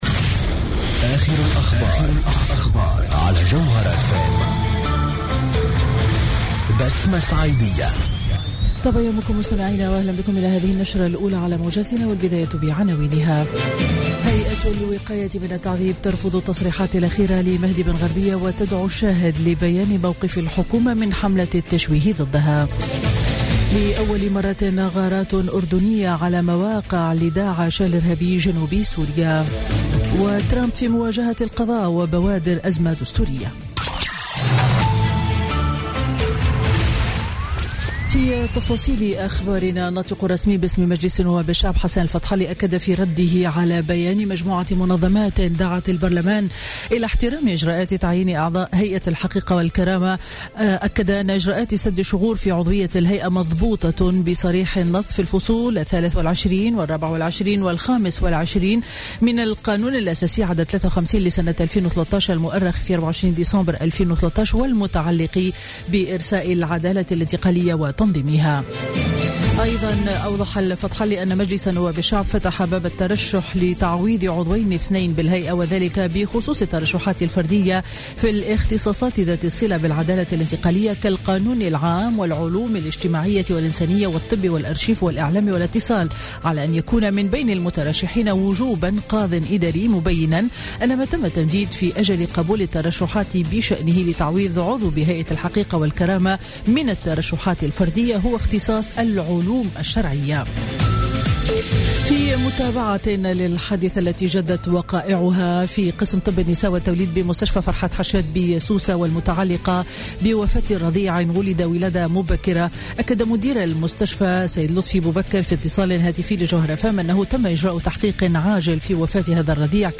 نشرة أخبار السابعة صباحا ليوم الأحد 5 فيفري 2017